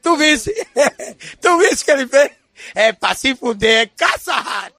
sale-notification.mp3